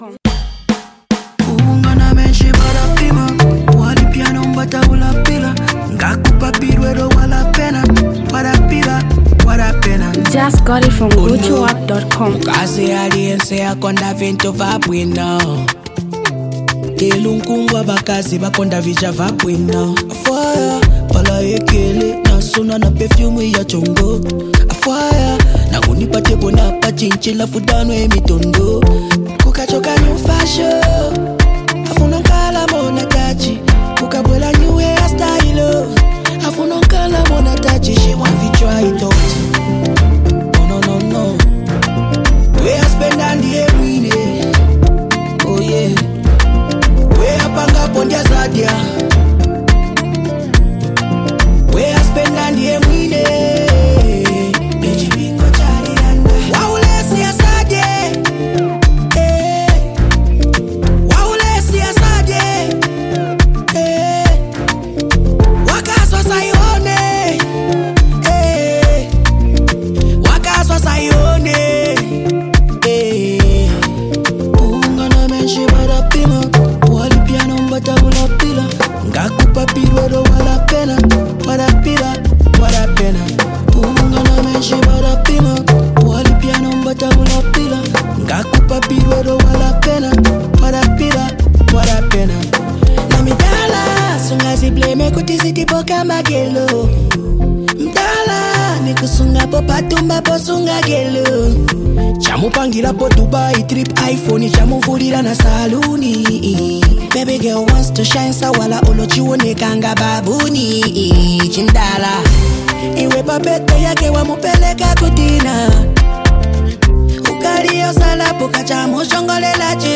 a fresh dancehall hit
Zambian music, dancehall
High-energy track with infectious beats and catchy melodies.